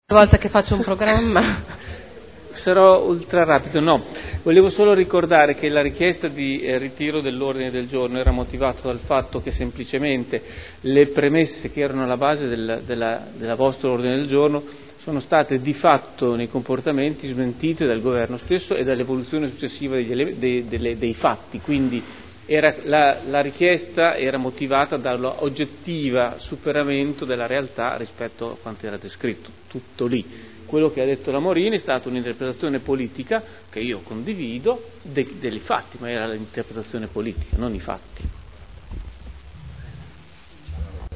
Seduta del 28/04/2011. Dibattito su mozione presentata dal Pd e su odg del Pdl sull'emergenza immigrazione.